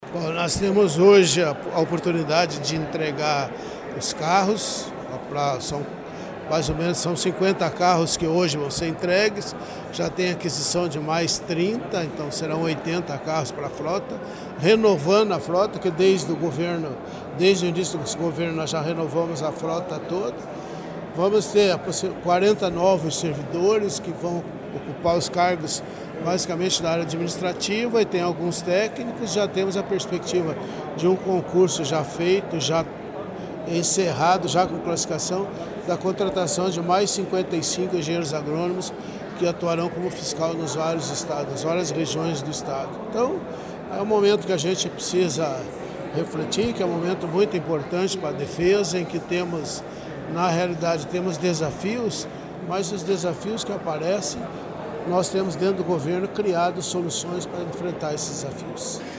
Sonora do diretor-presidente da Adapar, Otamir Cesar Martins, sobre os 324 novos servidores e entrega de 250 veículos para o sistema de agricultura